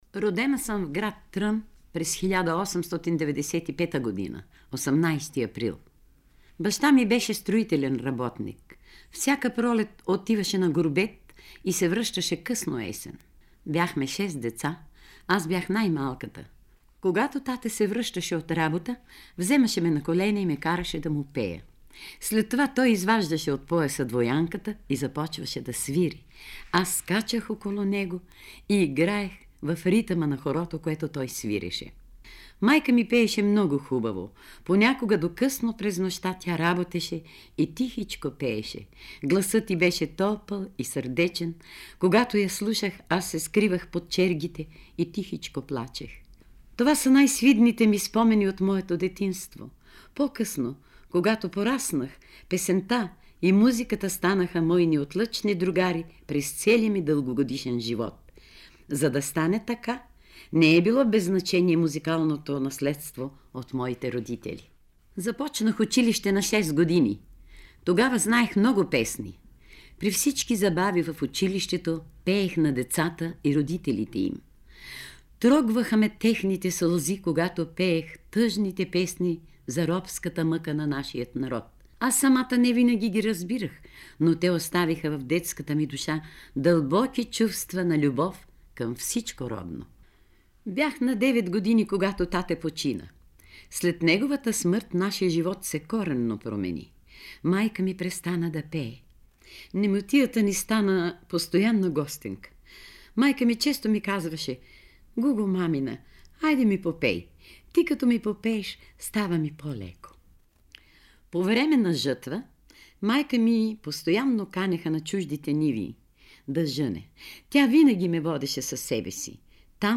За израстването си като личност и певица, разказва самата Пинджурова, запис 1970 година, Златен фонд на БНР: